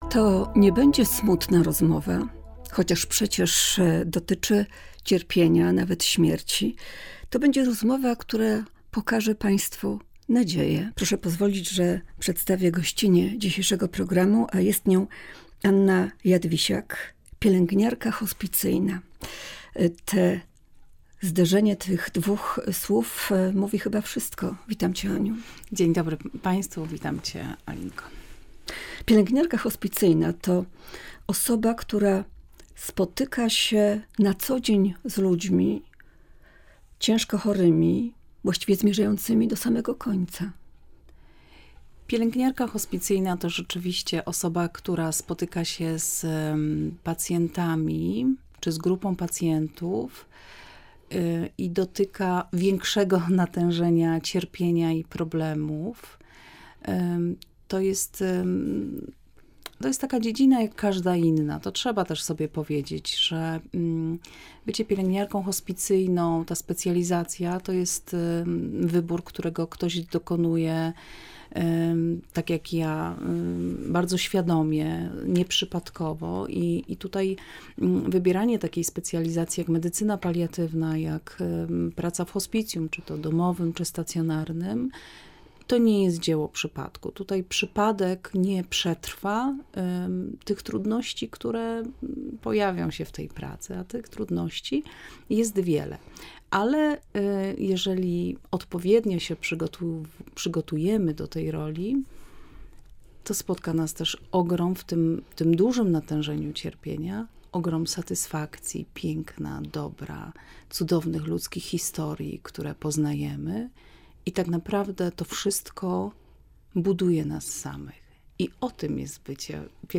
W tym wydaniu programu rozmawiamy między innymi o tym jak towarzyszyć cierpiącemu, jak samemu sobie unieść ciężar doświadczeń, własnych i cudzych reakcji.